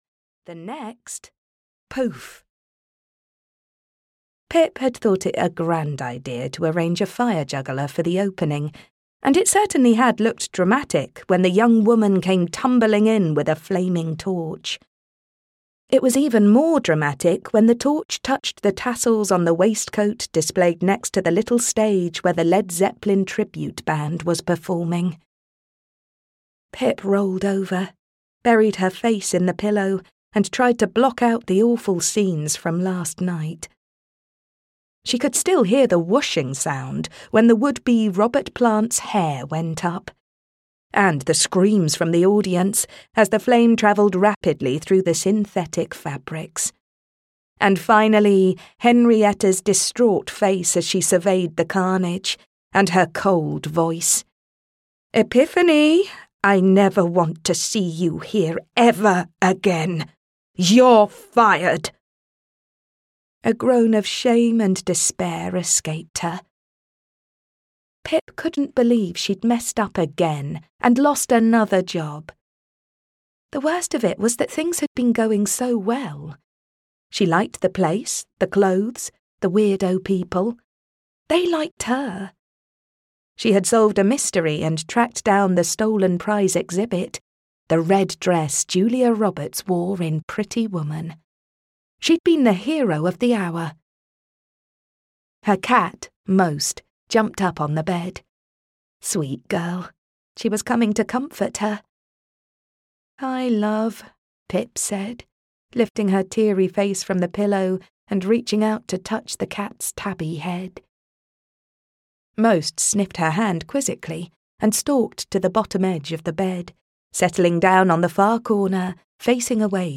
Death at the Gates (EN) audiokniha
Ukázka z knihy